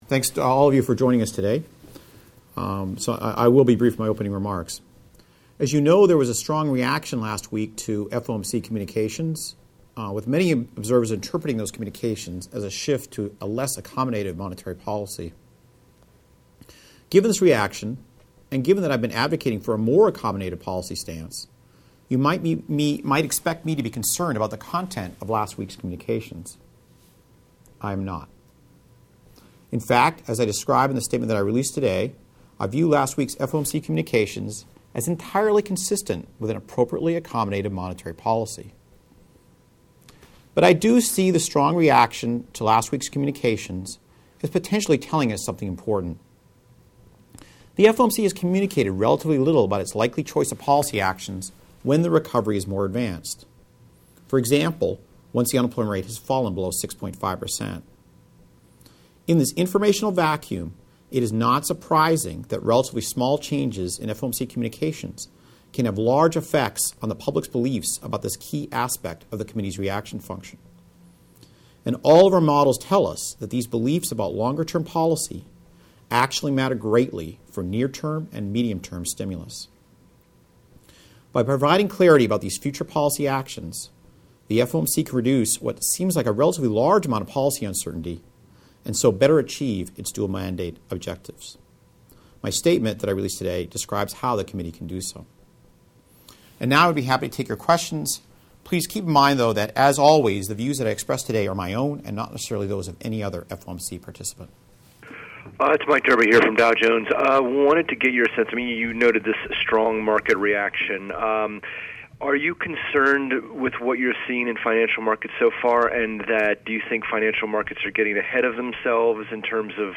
Audio: Media Q&A Note * In this note, I emphasize that recent Federal Open Market Committee communications are consistent with an appropriately accommodative monetary policy strategy.